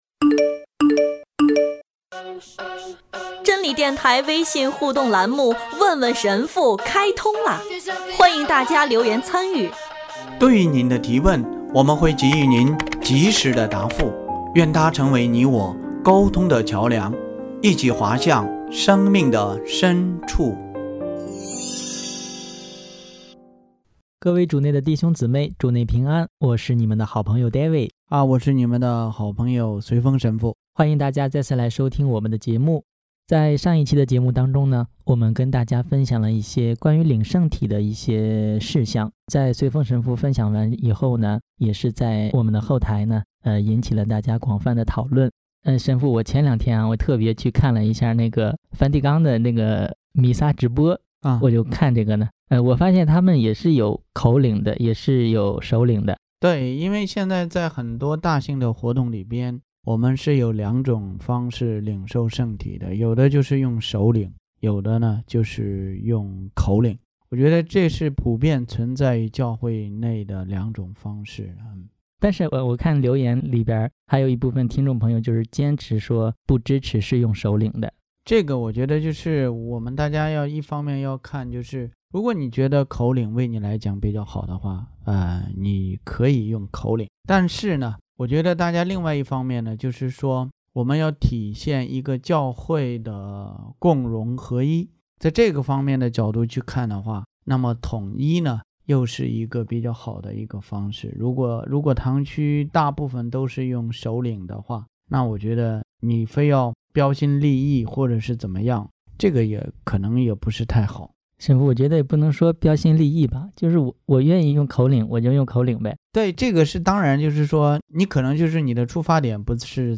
听众留言